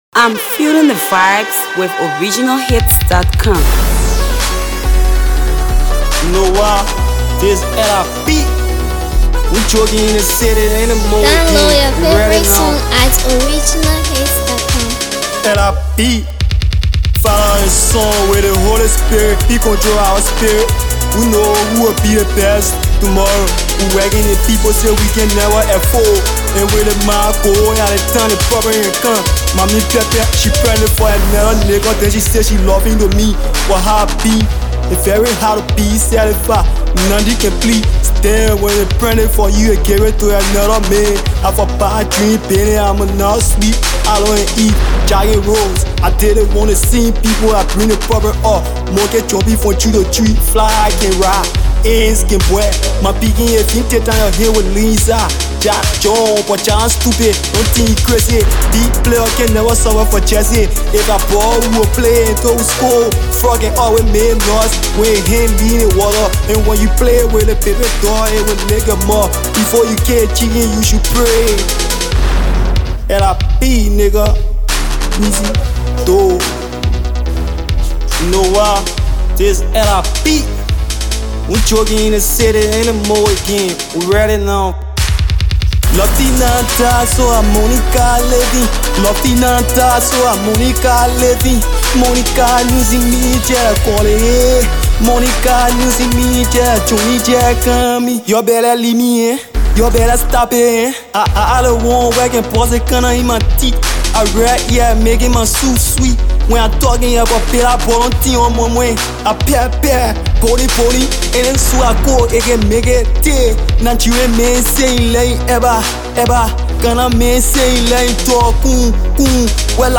Listen to this latest studio effort.